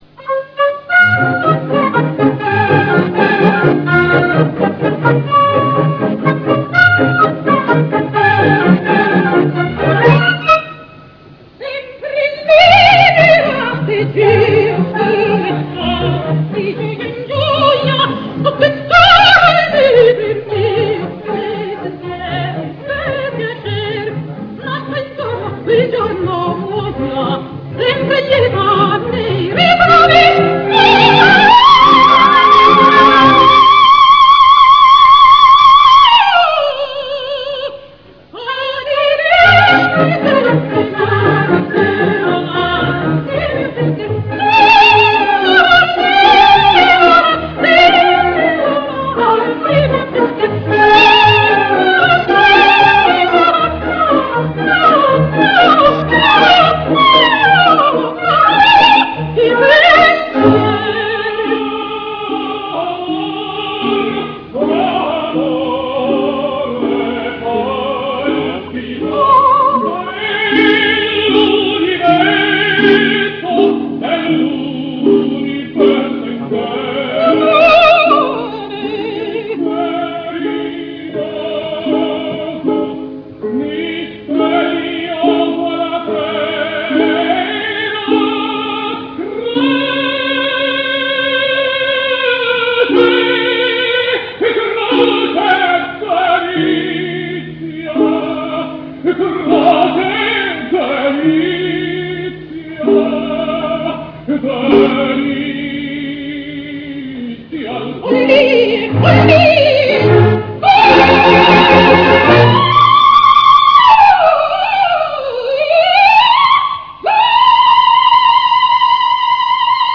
Sempre libera degg'io" dalla Traviata (Maria Callas, Alfredo Kraus; Orchestra del Teatro Nacional di Lisbona; 27 marzo 1958).
traviata_callas.ram